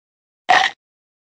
Cartoon Bite Sound
cartoon